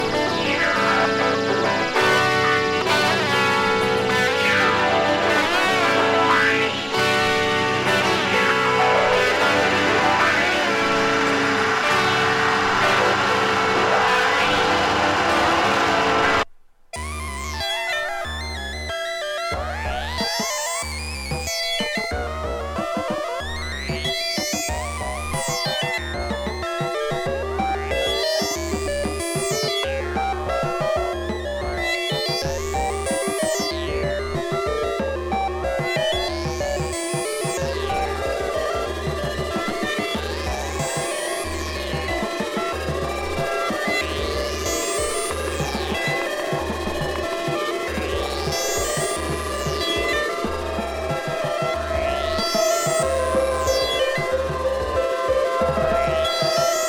アヴァンギャルドと、クラシカル両方を兼ね備えたサウンド。